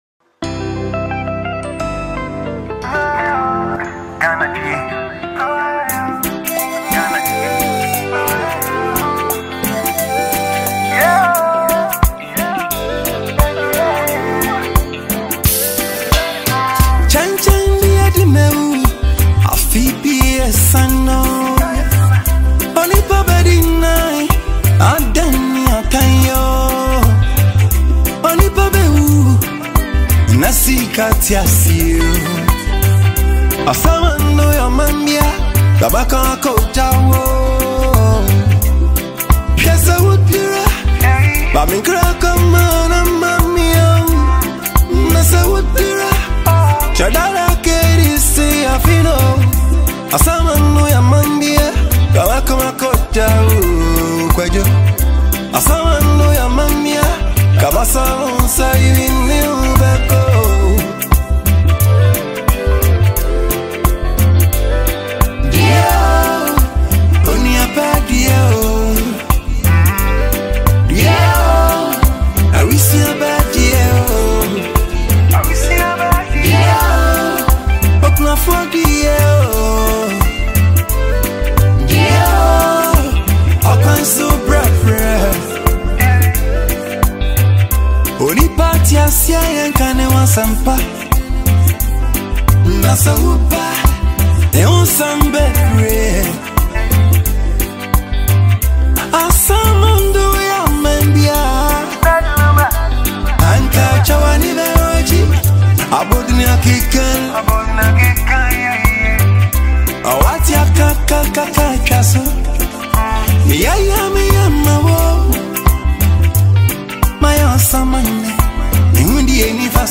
Ghanaian highlife star
tribute song